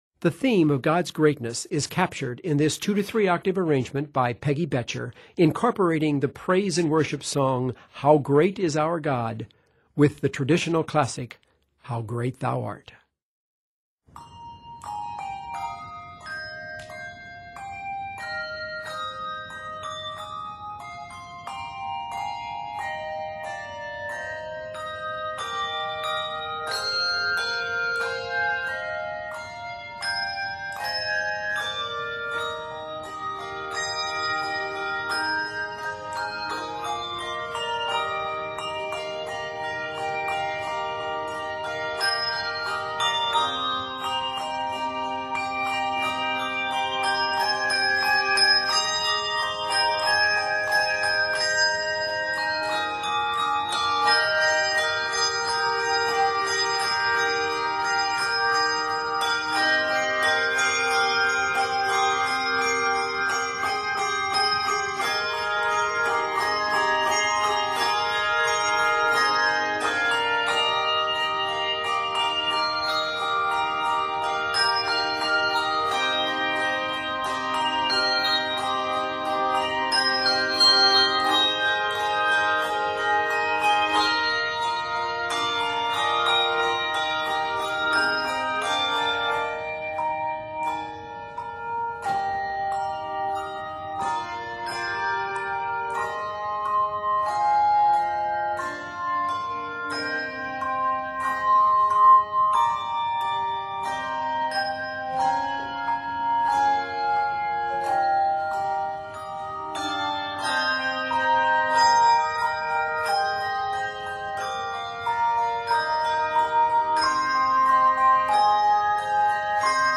70 measures in length, this medley is set in Eb Major.